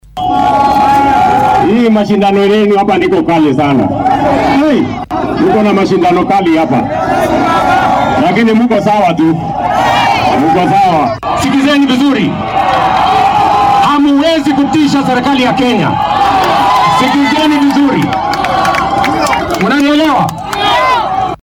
Dhallinyarada maalinkaasi buuqa ku bartilmaameedsaday siyaasiyiinta ayaa qaar ka mid ah madaxda ka hor istaagay in ay dadweynaha la hadlaan iyadoo ay xaaladdu sidatan ahayd.
Buuqa-Isiolo.mp3